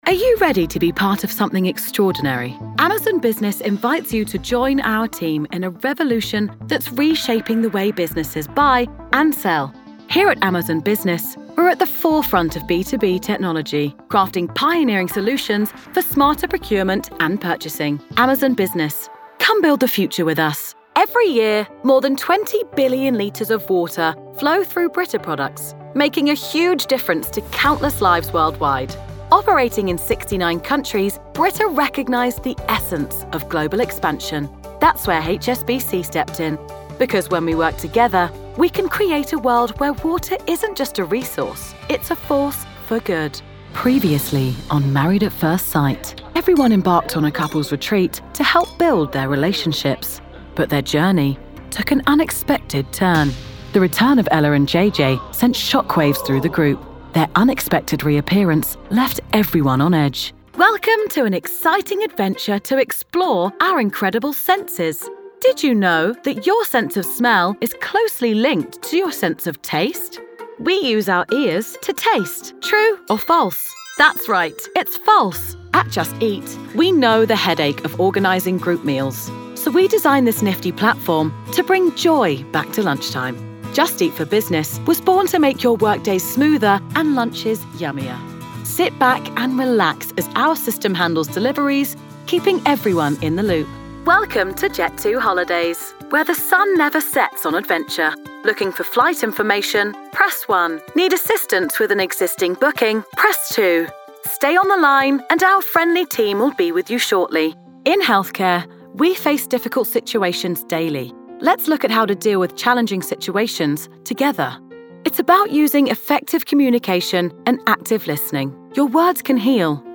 Narration
London based youthful, husky and chatty female British Voiceover. Relatable, current, and versatile, with both character work and accent work!